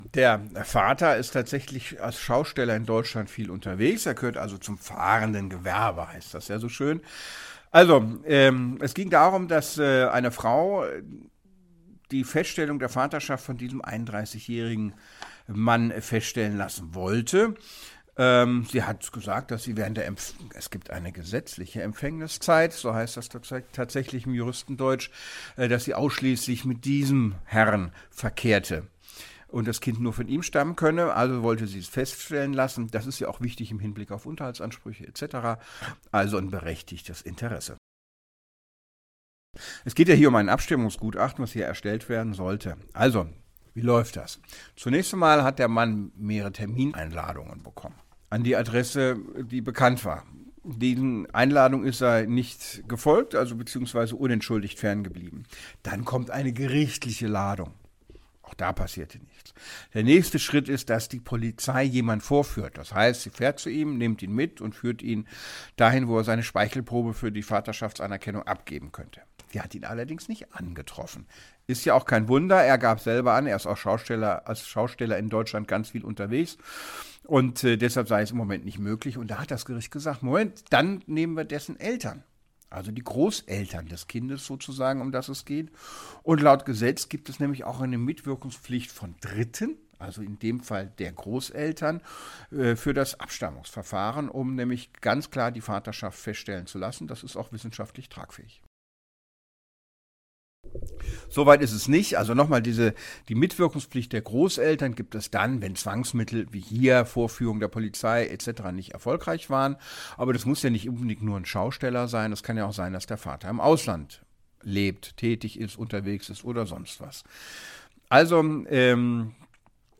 Kollegengespräch: Müssen Großeltern auch zum Abstammungstest?
DAV, O-Töne / Radiobeiträge, Ratgeber, Recht, , , ,